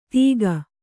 ♪ tīga